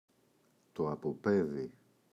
αποπαίδι, το [apo’peði] – ΔΠΗ